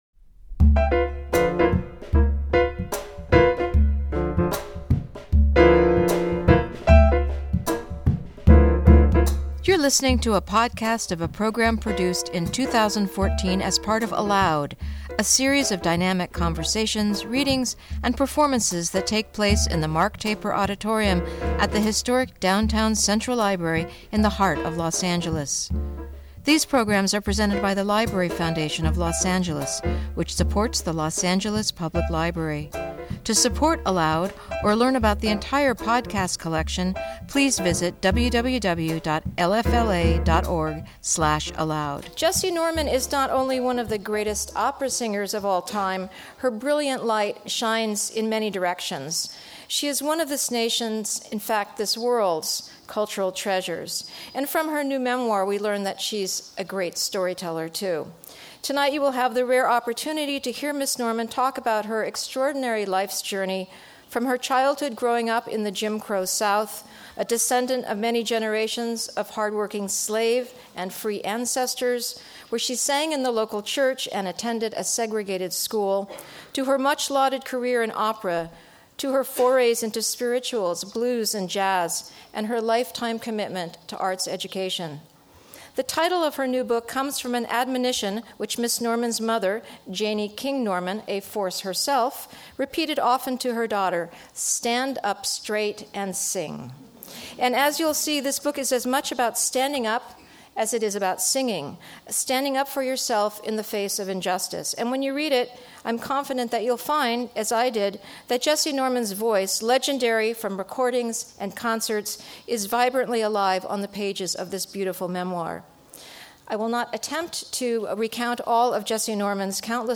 Jessye Norman In Conversation With Deborah Borda